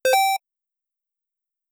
scoreup.wav